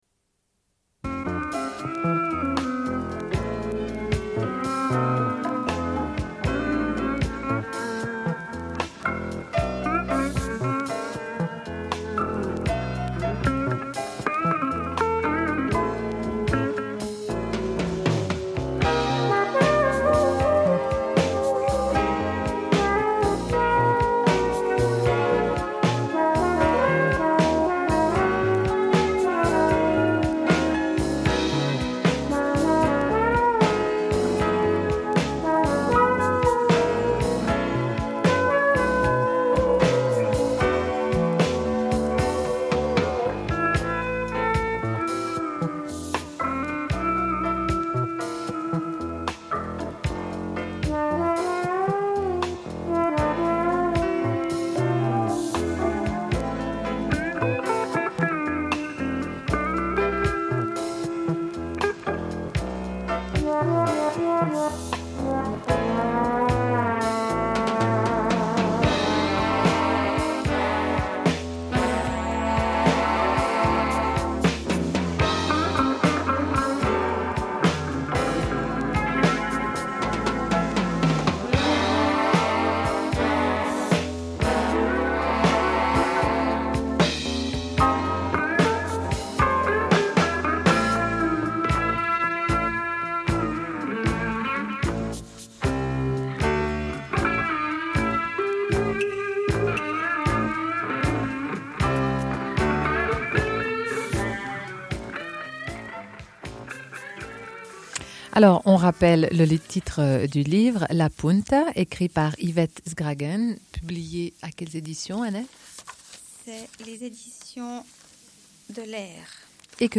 Une cassette audio, face B28:41